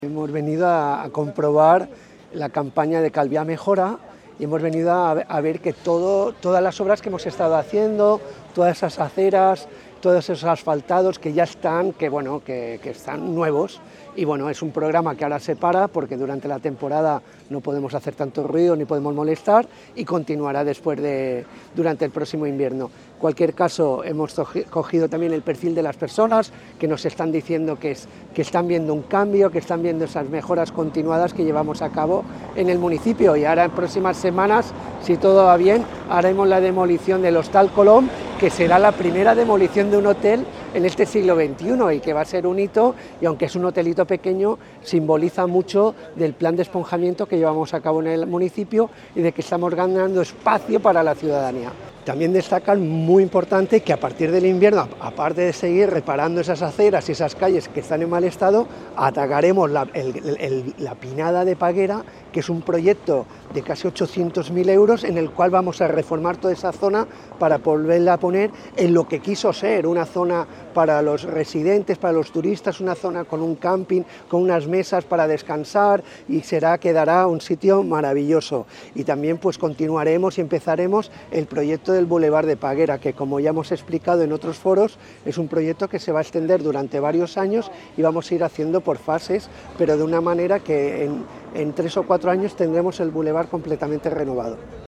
declaraciones-alcalde-obras-peguera.mp3